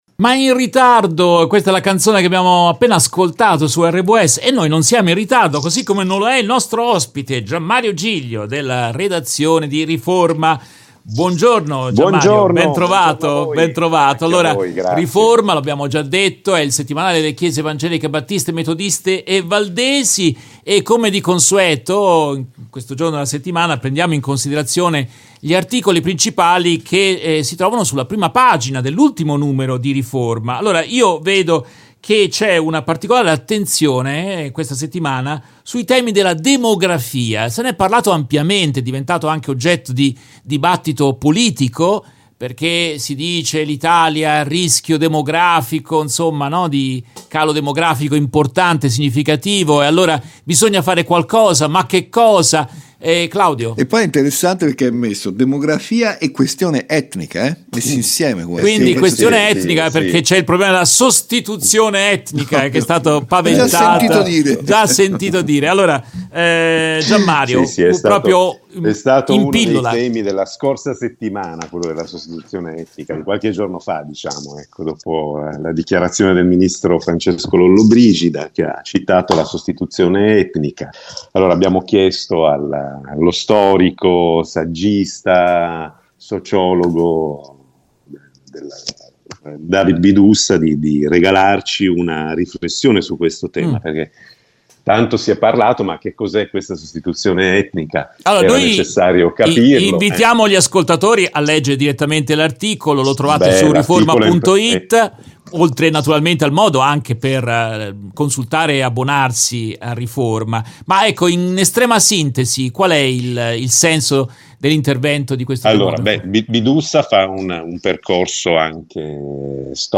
Continuano le conversazioni con la redazione del settimanale Riforma, organo ufficiale delle Chiese evangeliche battiste, metodiste e valdesi. Oggi vi proponiamo un’intervista